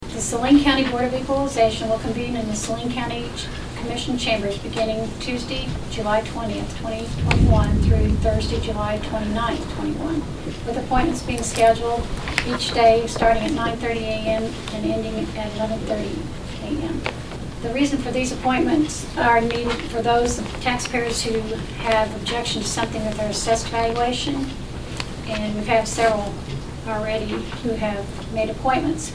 During the meeting of the Saline County Commission on Thursday, June 24, Clerk Debbie Russell read a public notice about the county’s Board of Equalization hearings scheduled for next month.